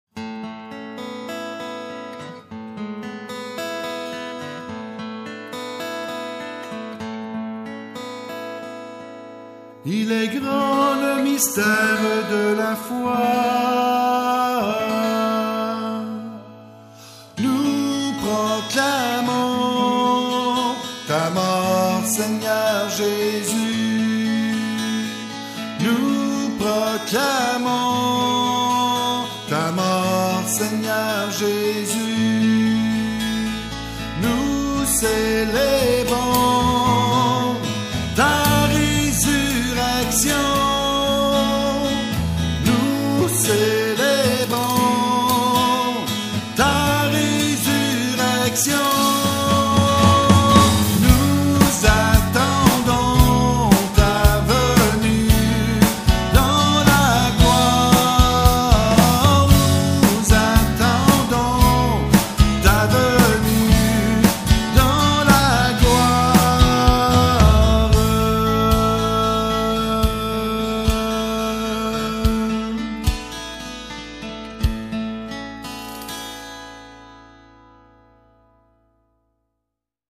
Chants divers
anamnese_chant.mp3